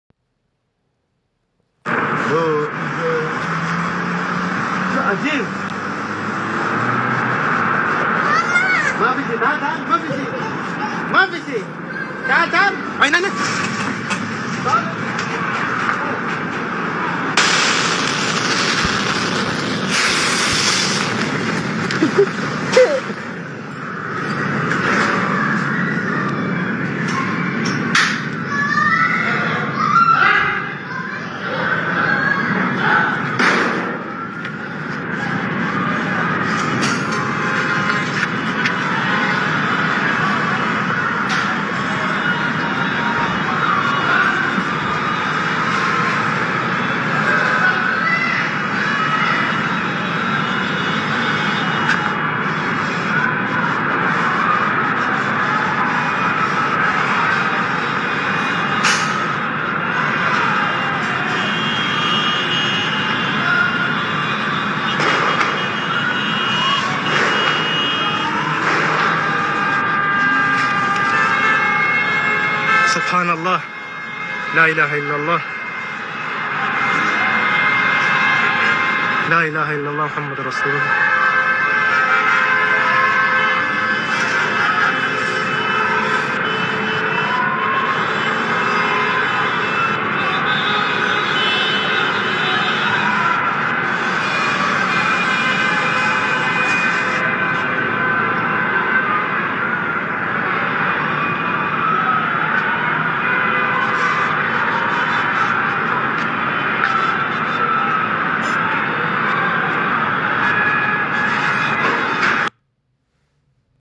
explosion beyrouth compilation - Bouton d'effet sonore